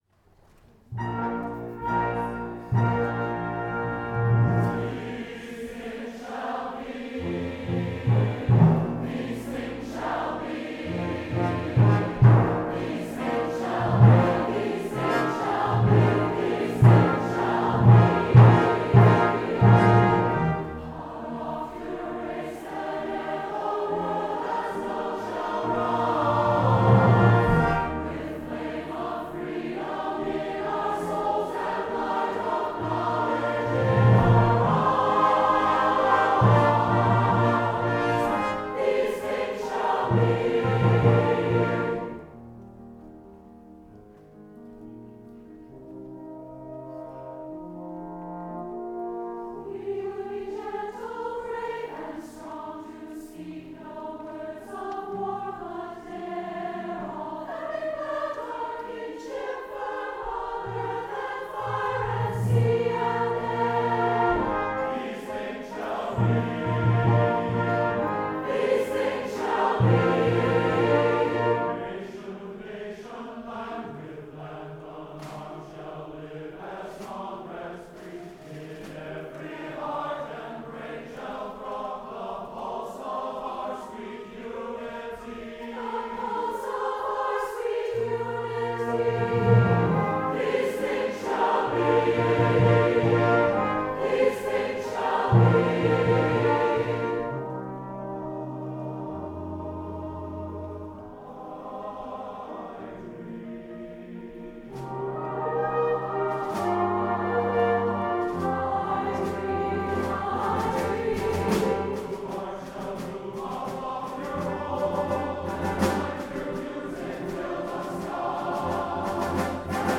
SATB, brass, and piano version